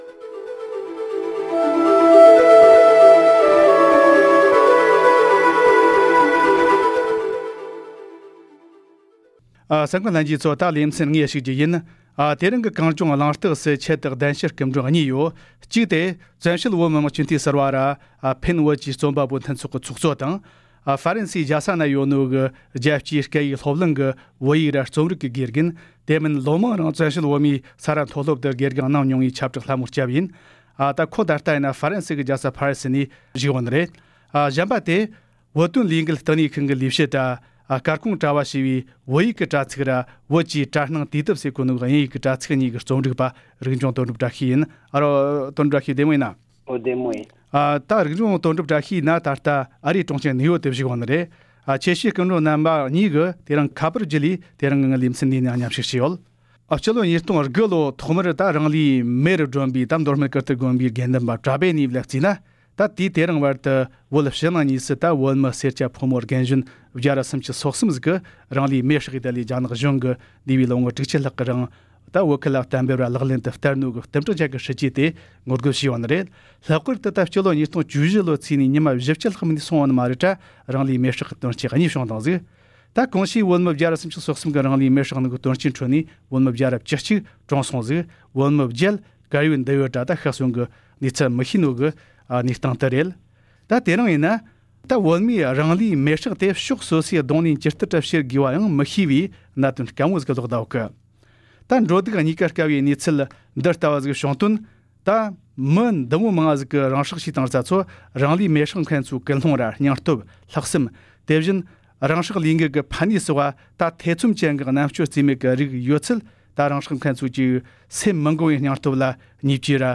བཅས་གནད་དོན་ཐད་གནད་དོན་དབྱེ་ཞིབ་པ་དང་མཉམ་དུ་བགྲོ་གླེང་ཞུས་པ་ཞིག་གསན་རོགས་གནང་།།